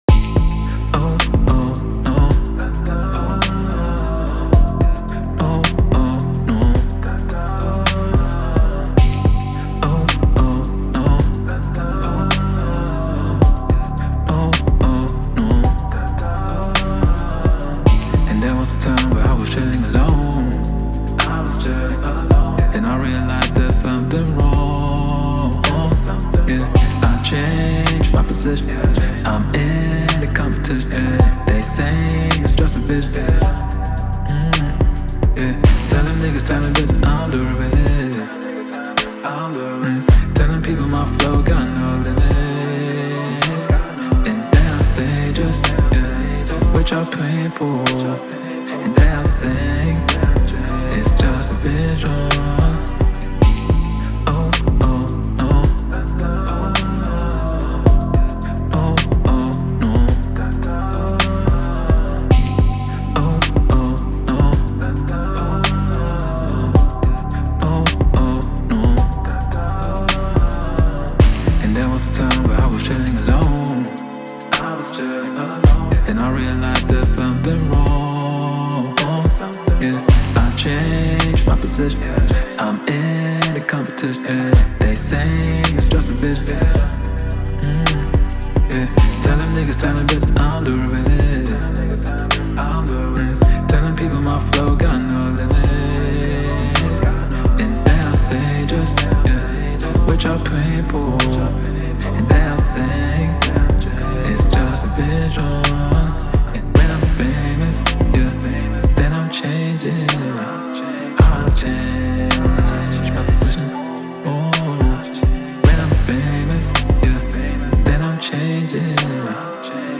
His signature sound is uplifting, versatile and magical.